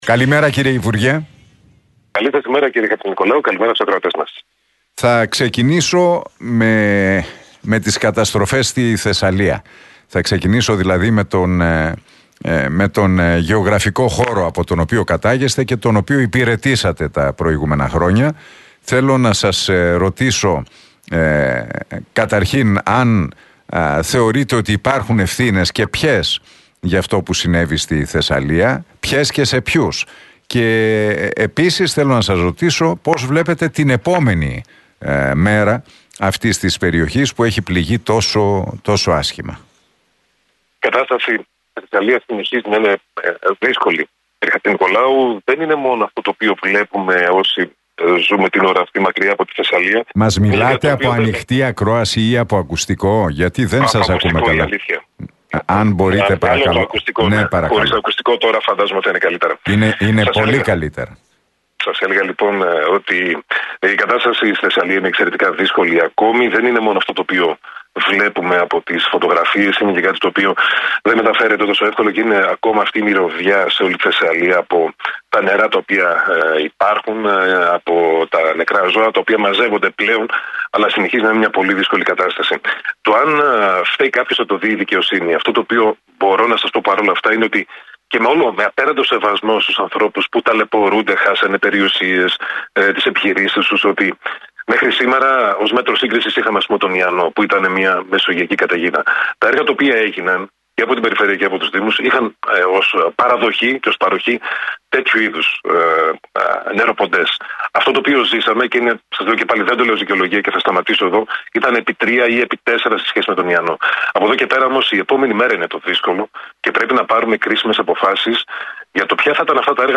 Ο υπουργός Ψηφιακής Διακυβέρνησης, Δημήτρης Παπαστεργίου, παραχώρησε συνέντευξη στην εκπομπή του Νίκου Χατζηνικολάου στον Realfm 97,8.